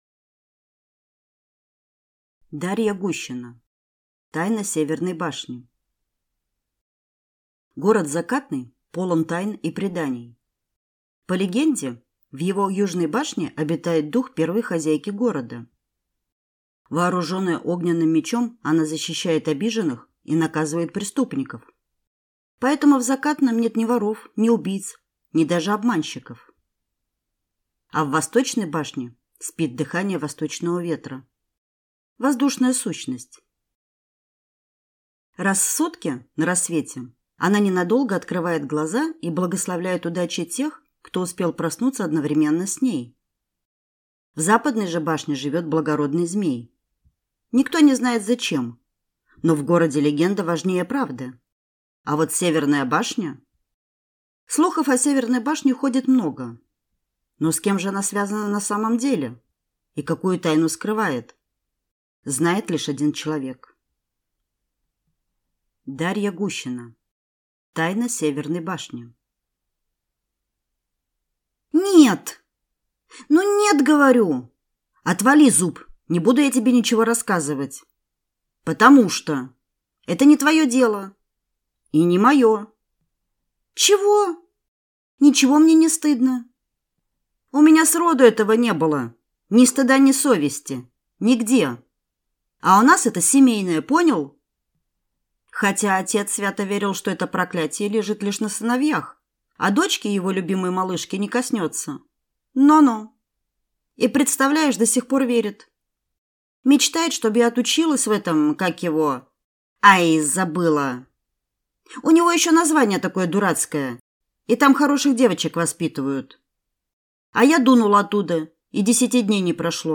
Аудиокнига Тайна Северной башни | Библиотека аудиокниг
Прослушать и бесплатно скачать фрагмент аудиокниги